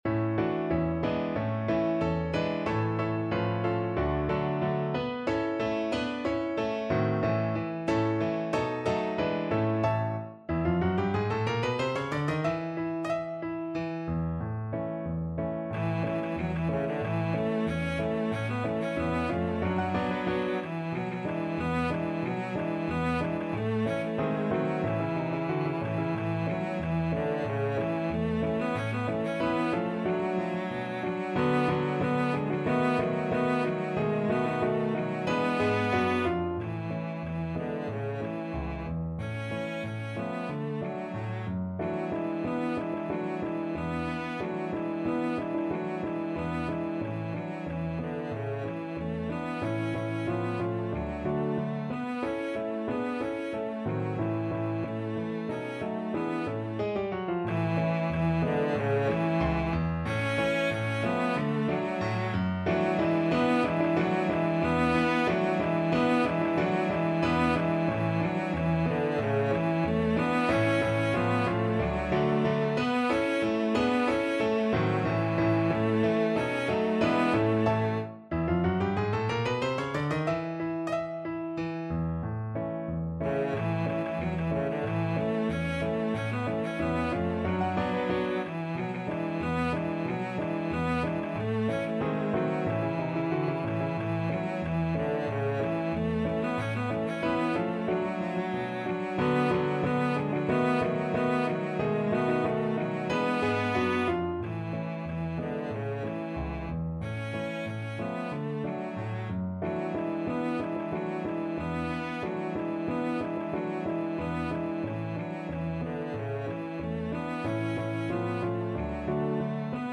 Allegretto =92
2/4 (View more 2/4 Music)
Traditional (View more Traditional Cello Music)